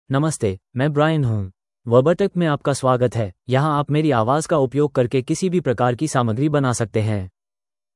MaleHindi (India)
Brian is a male AI voice for Hindi (India).
Voice sample
Listen to Brian's male Hindi voice.
Brian delivers clear pronunciation with authentic India Hindi intonation, making your content sound professionally produced.